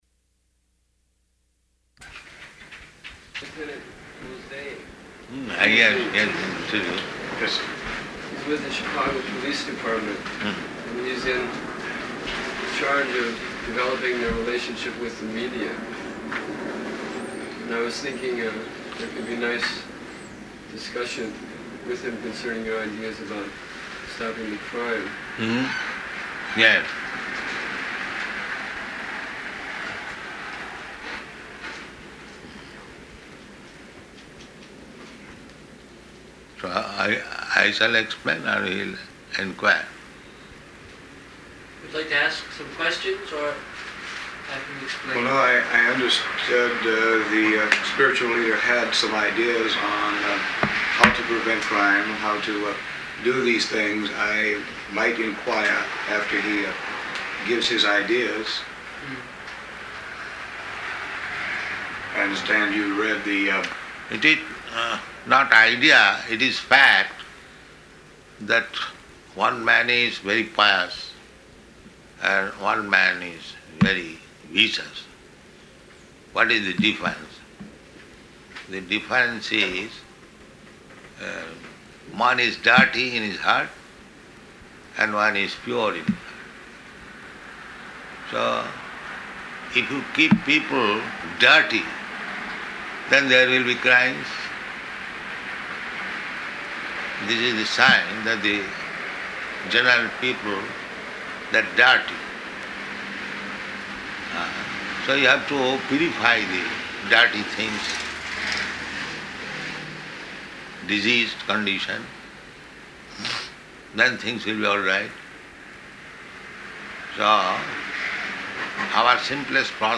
-- Type: Conversation Dated: July 5th 1975 Location: Chicago Audio file